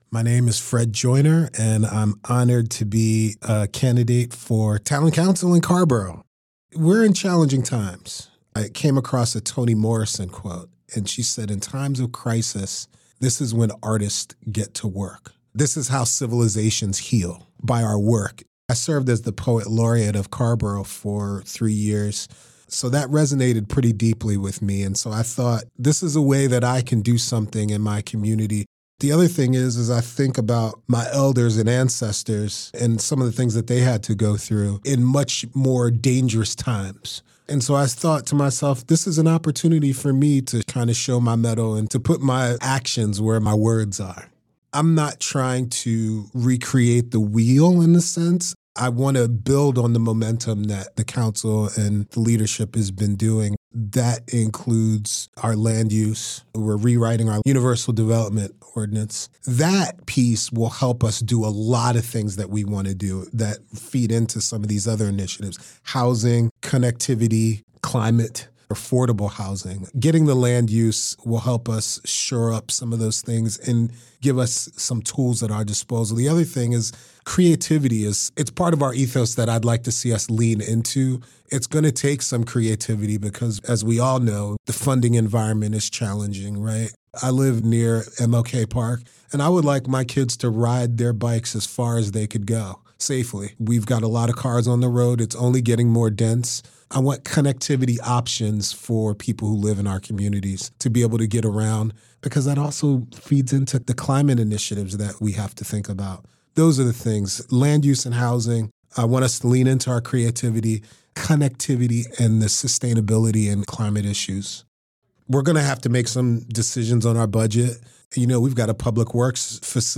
97.9 The Hill spoke with each candidate, asking these questions that are reflected in the recorded responses: